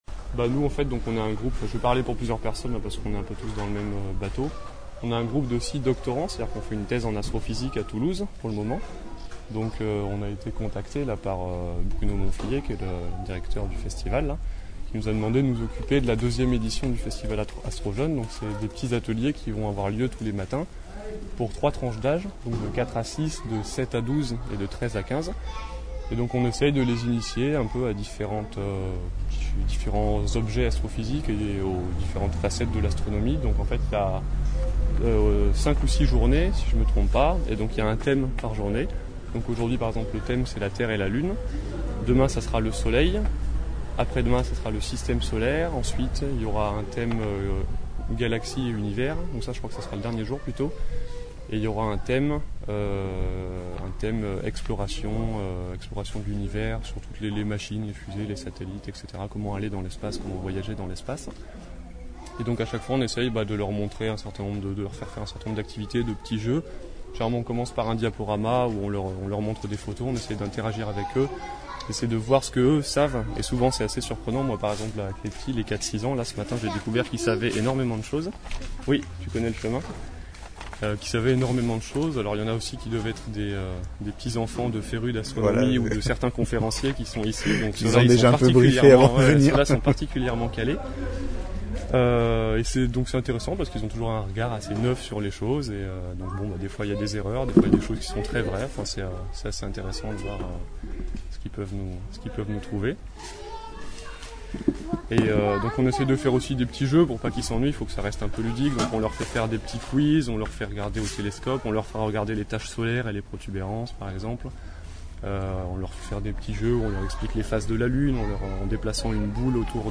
XVIIème festival d’astronomie de Fleurance
un jeune doctorant